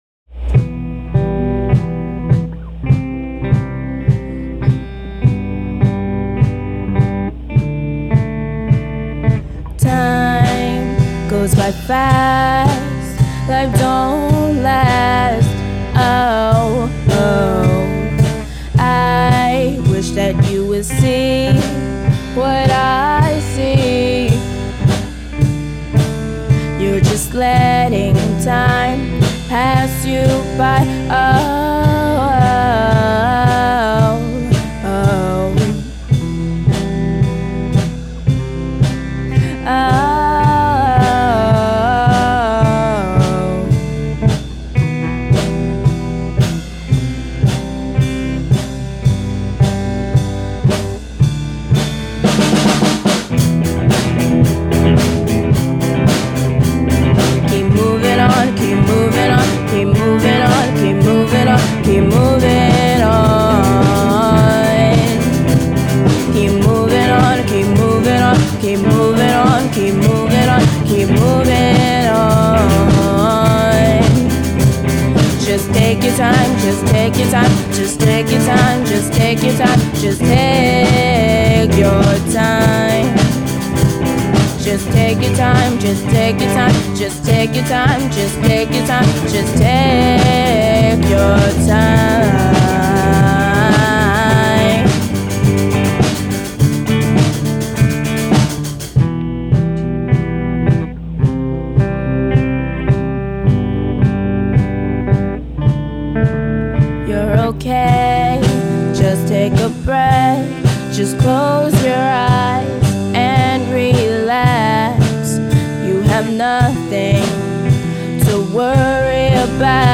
3:21 Eppiku 2018 Teen Rock Camp 2:28 Infectious Eggs 2018 Teen Rock Camp 2:11 Majorettes 2018 Teen Rock Camp 3:07 missleading 2018 Teen Rock Camp 3:49 R!OT 2018 Teen Rock Camp 3:21 wyldflowers 2018 Teen Rock Camp